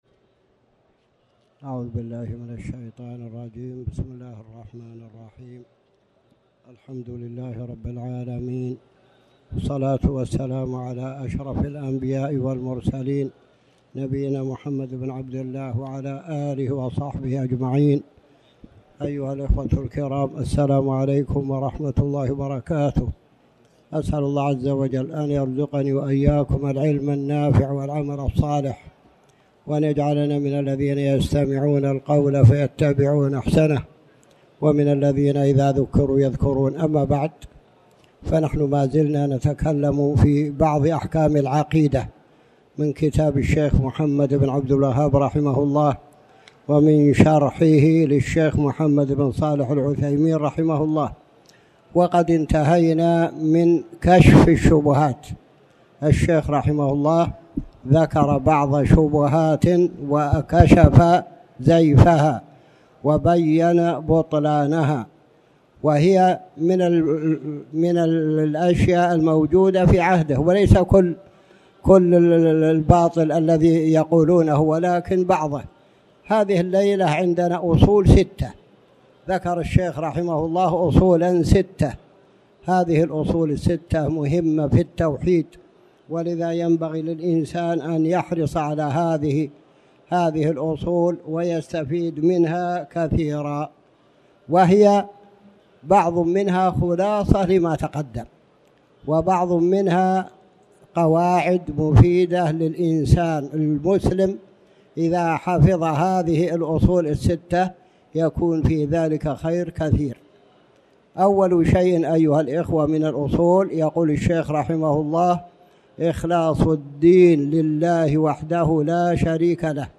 تاريخ النشر ١١ جمادى الآخرة ١٤٣٩ هـ المكان: المسجد الحرام الشيخ